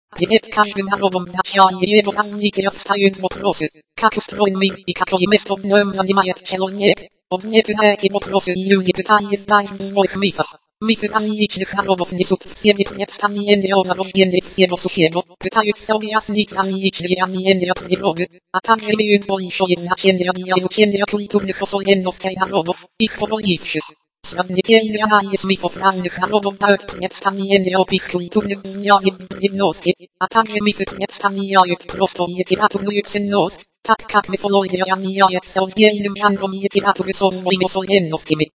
eSpeak Win - это адоптация под операционную систему MS Windows компактного мультиязычного некоммерческого синтезатора речи eSpeak, также известного по системам Linux, Mac OS, RISC OS и доступного просто в виде исходного кода на языке C++.
К сожалению, из-за малой голосовой базы качество речи eSpeak на большинстве языков оставляет желать лучшего.
Речь обладает довольно сильным акцентом.
Скачать демонстрационный аудиофайл одного из женских голосов [228 kB]